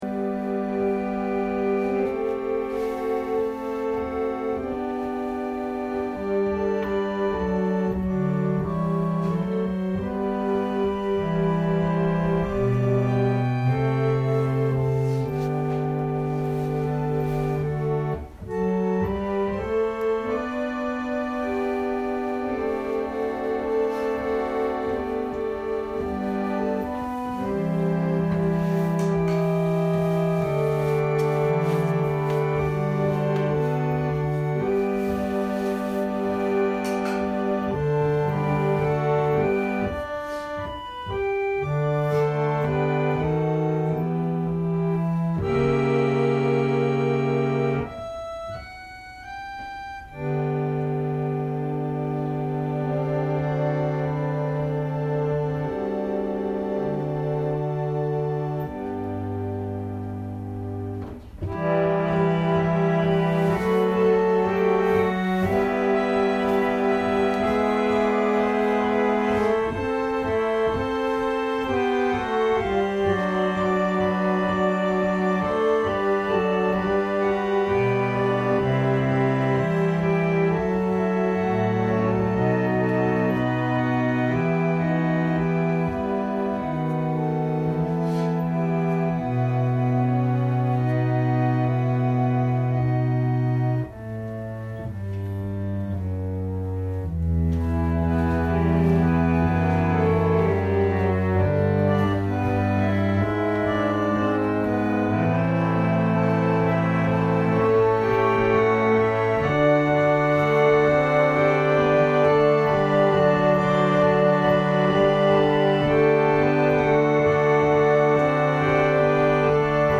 千間台教会。説教アーカイブ。